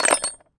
metal_small_movement_02.wav